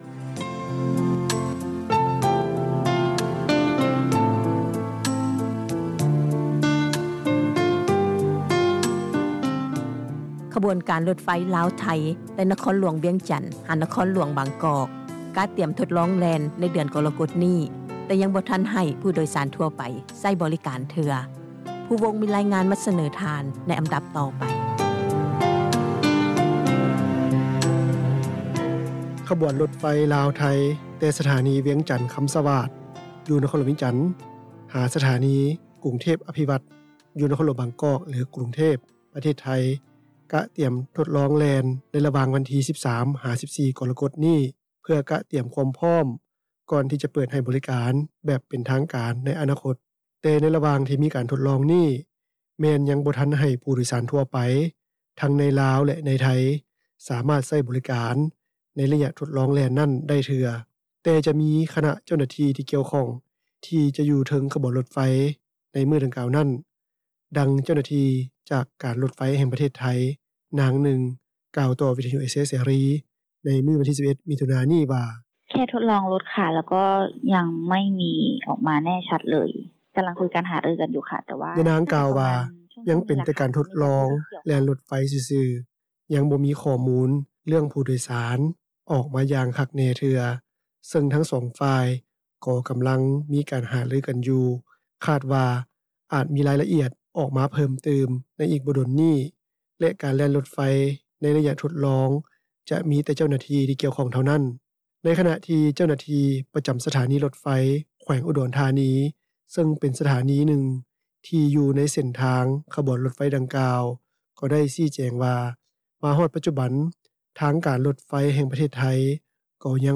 ດັ່ງຊາວບ້ານ ນາງໜຶ່ງ ກ່າວວ່າ:
ດັ່ງຜູ້ໂດຍສານ ລົດໄຟລາວ-ຈີນ ນາງໜຶ່ງ ກ່າວວ່າ: